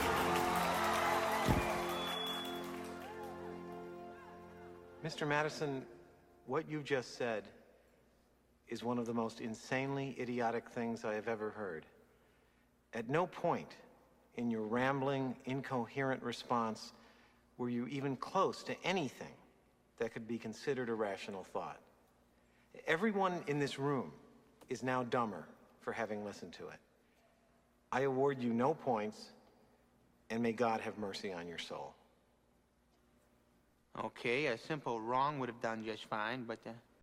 Rambling, Inchoerent